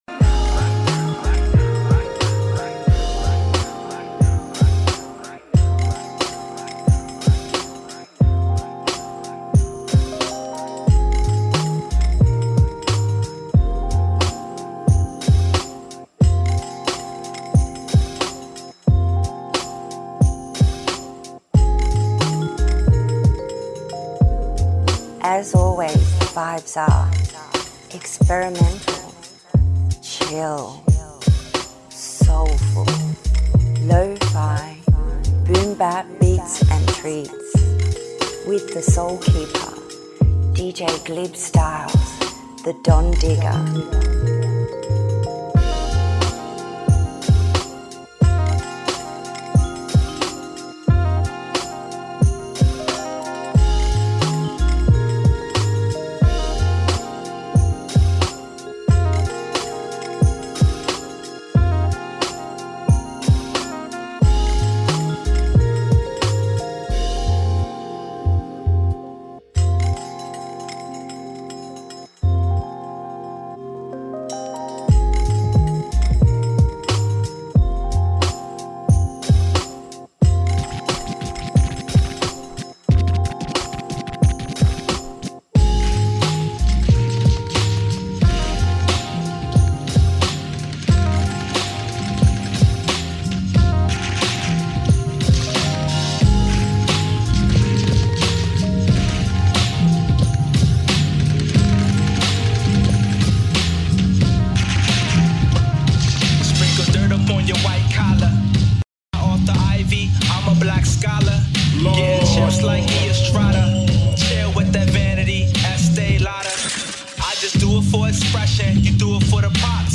Chill Hip Hop Soul and Lo-Fi Beats (*1 hour portion)